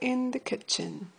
pops~
Tag: 点击 毛刺 持久性有机污染物 点击 弹出 颗粒状